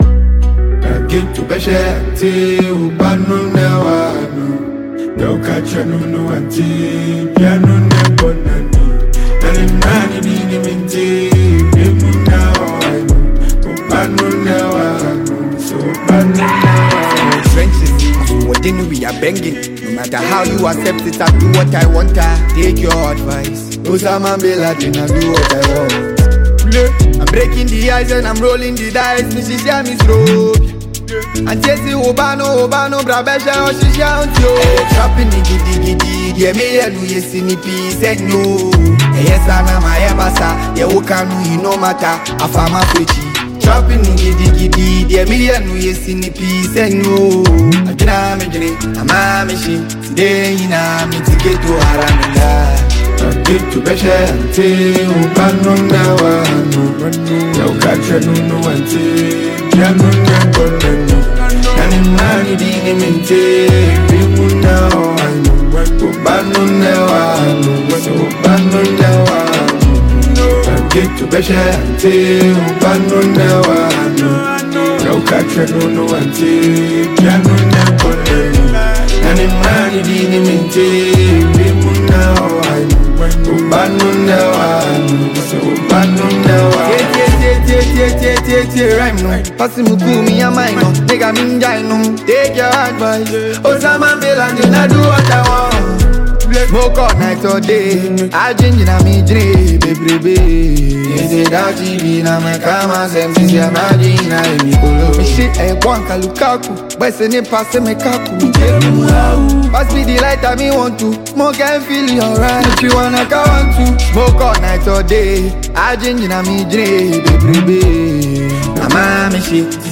Ghana Music Music